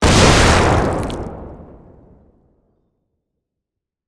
CosmicRageSounds / wav / general / combat / weapons / rocket / flesh1.wav
flesh1.wav